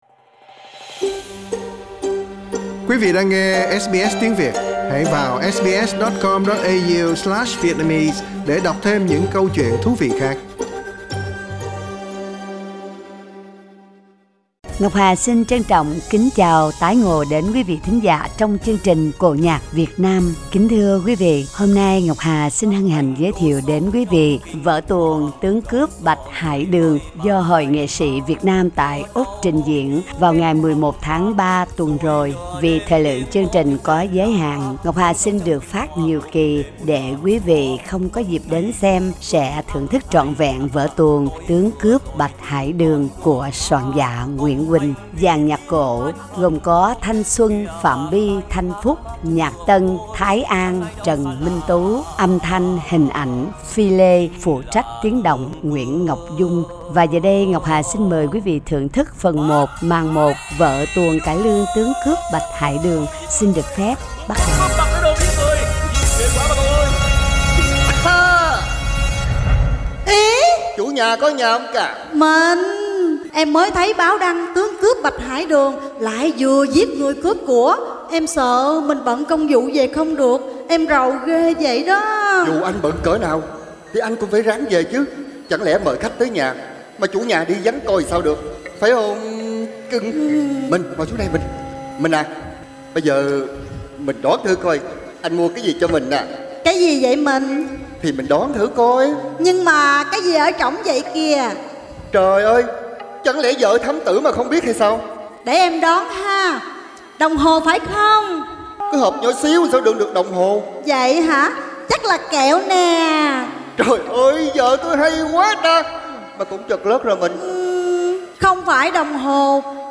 Vở cải lương 'Tướng Cướp Bạch Hải Đường' của soạn giả Nguyễn Huỳnh, là vở tuồng rất ăn khách trên sân khấu cải lương trước năm 1975.
Xin mời quý vị cùng thưởng thức phần 1 vở cải lương 'Tướng cCớp Bạch Hải Đường', do anh chị em Nghệ sĩ Úc Châu trình diễn ngày 11/03/22 vừa qua.